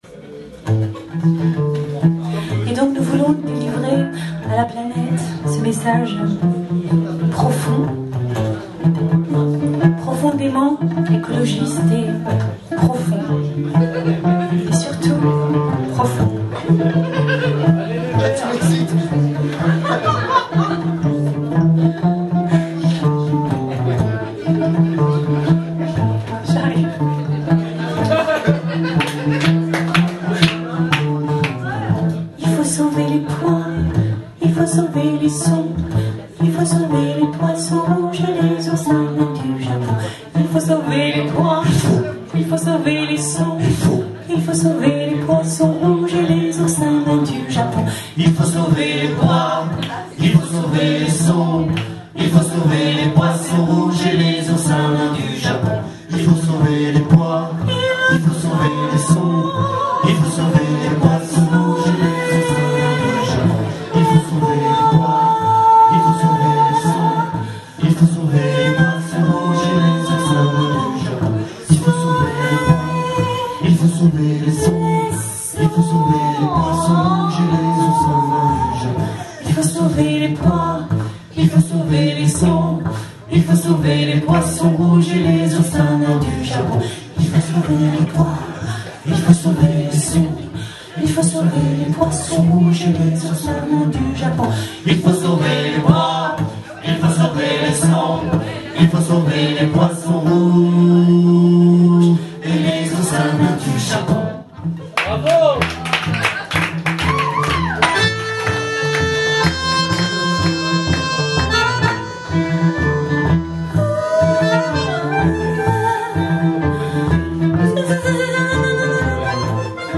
accordéon
violon
contrebasse